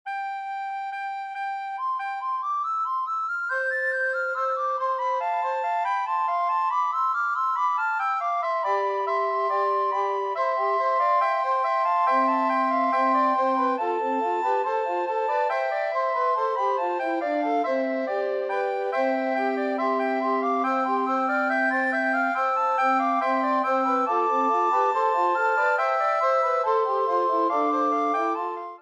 S A T B